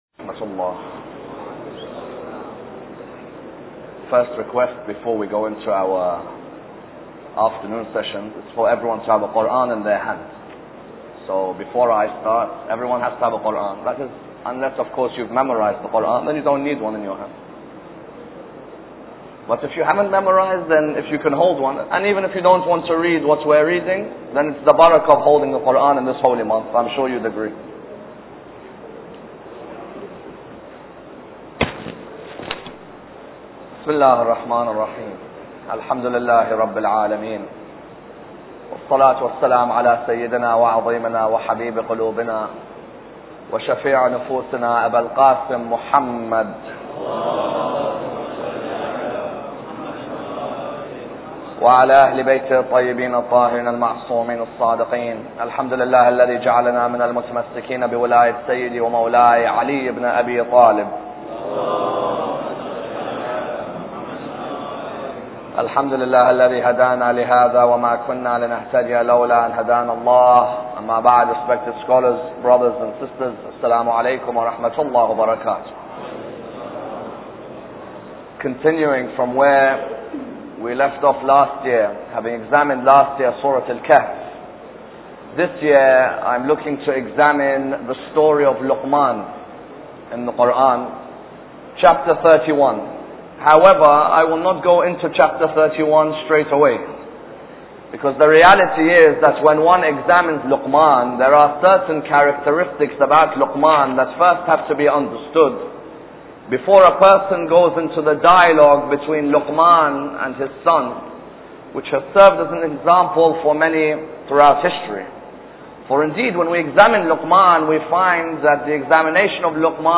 Lecture 1